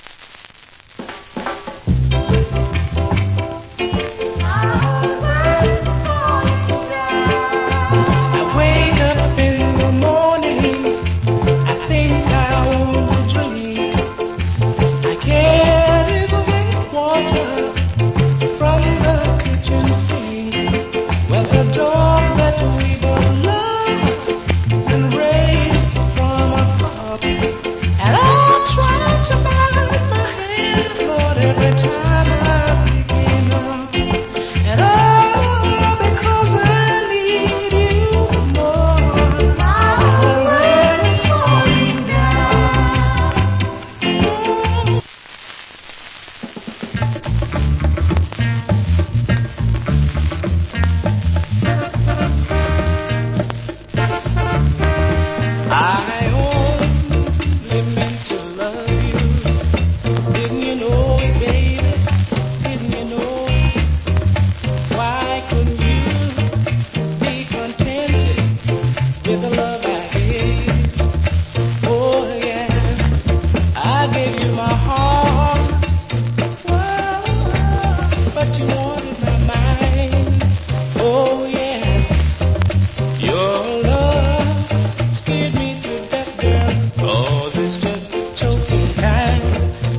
Old Hits Early Reggae.